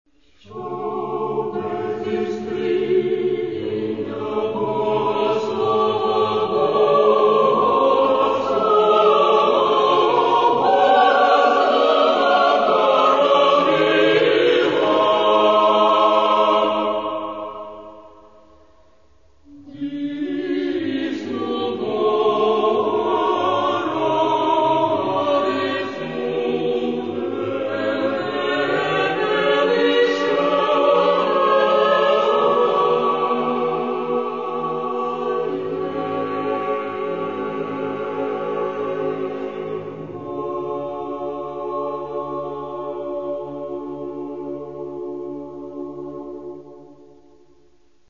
Каталог -> Церковная